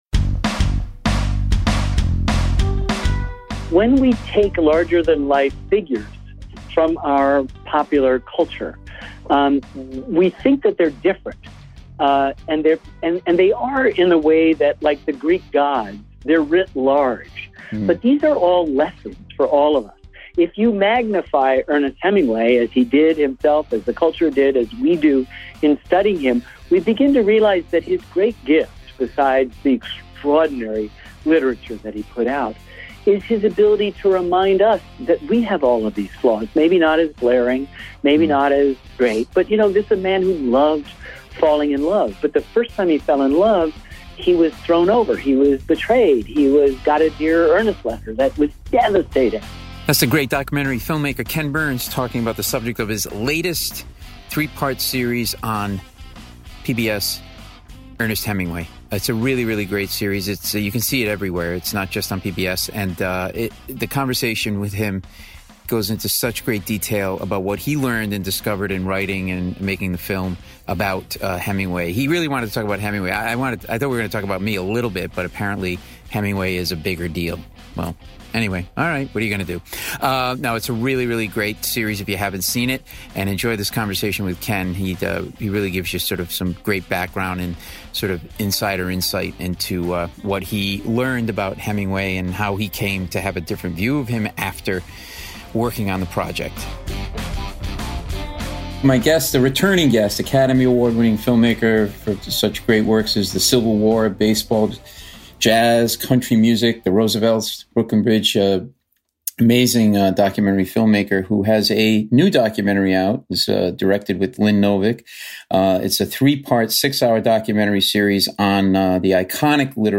Ken Burns - Emmy Winning Documentary Filmmaker - "The Civil War", "Baseball," "The Central Park Five," "Hemingway" (Paul Mecurio interviews Kenneth Burns; 08 Apr 2021) | Padverb
The greatest documentary filmmaker of his generation speaks passionately about the subject of his latest PBS series, Ernest Hemingway.